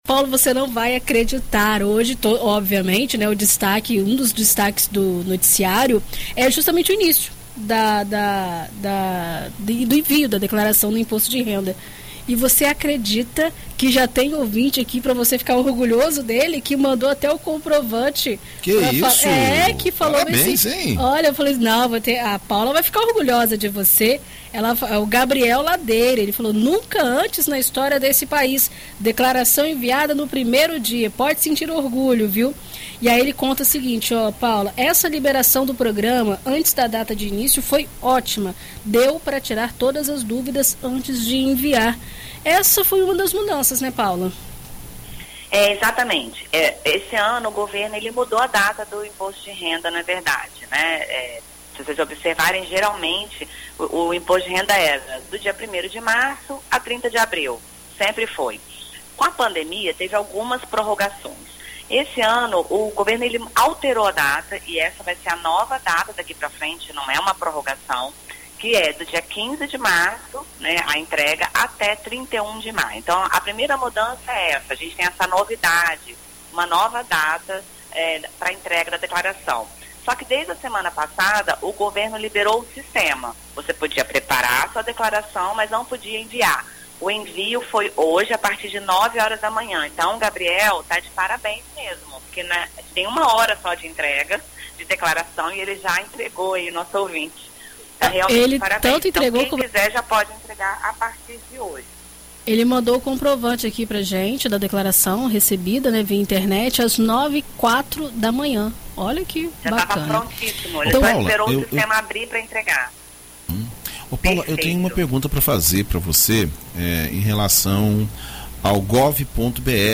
Em entrevista a BandNews FM ES nesta quarta-feira (15)